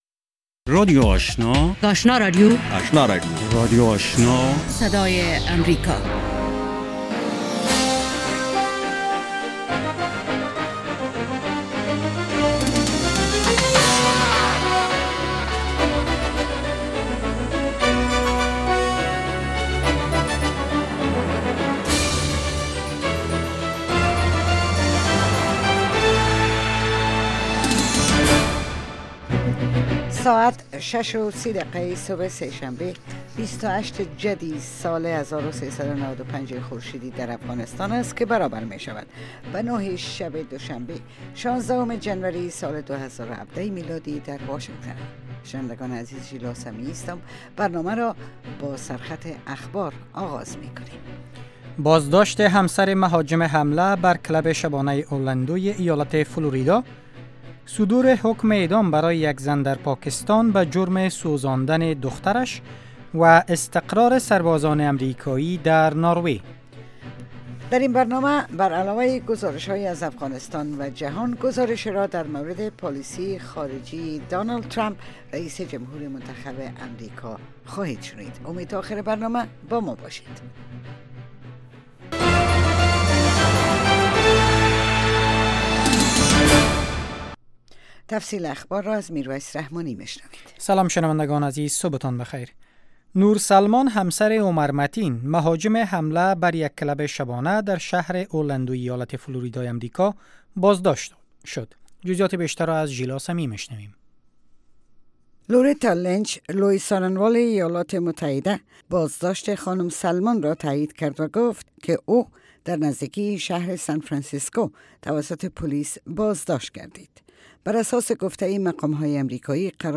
دومین برنامه خبری صبح